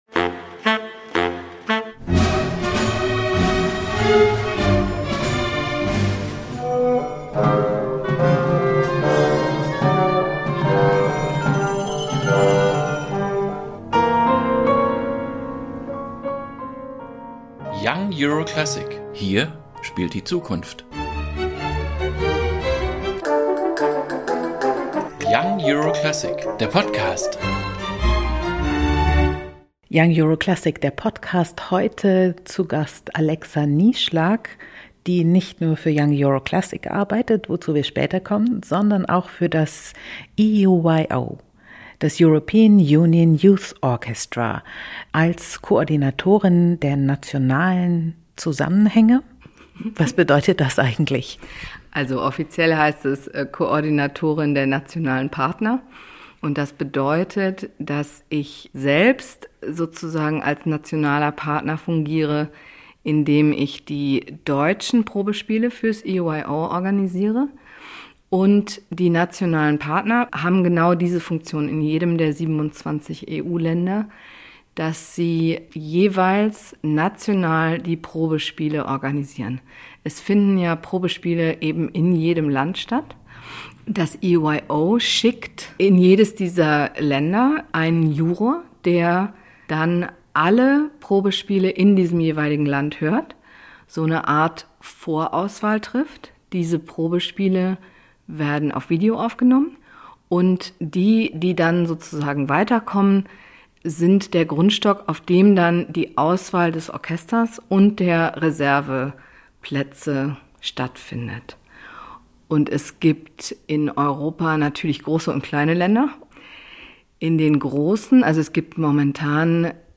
Konzerteinführung 08.08.2022 | European Union Youth Orchestra ~ Young Euro Classic. Der Podcast